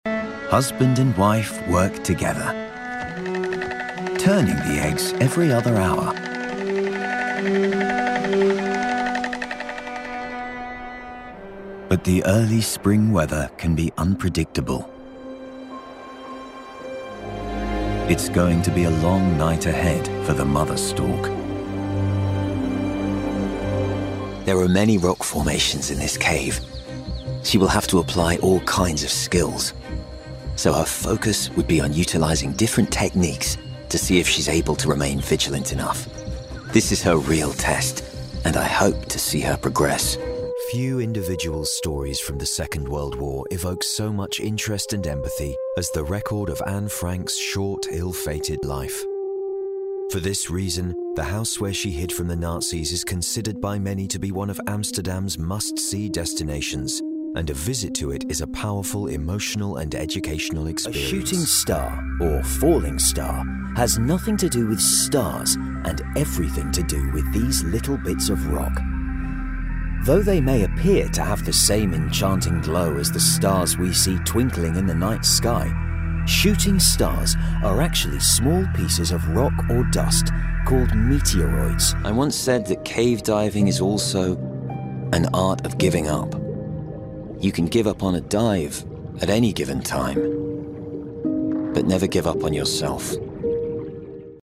Documentaires
Microphone à valve à condensateur cardioïde Sontronics Aria
Microphone dynamique SHURE SM7B